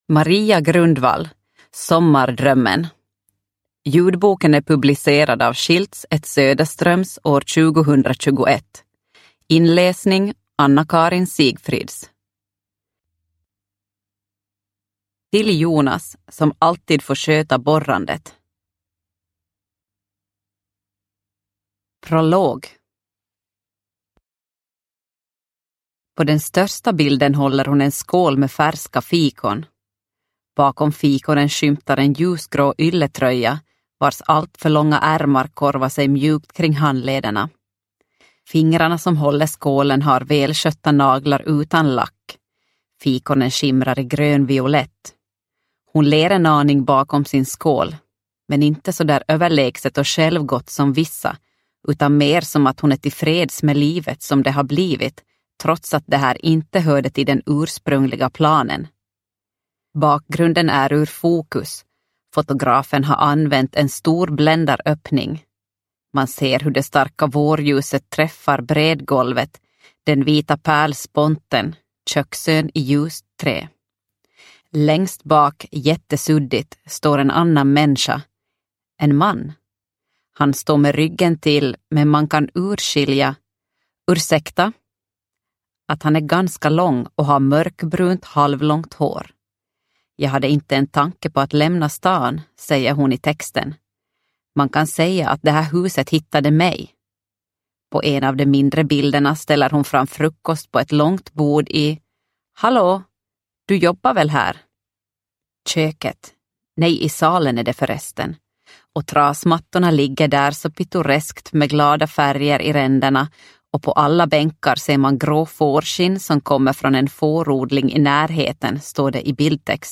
Sommardrömmen – Ljudbok – Laddas ner